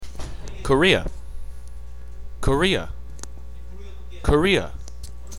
Here is how we pronounce this word: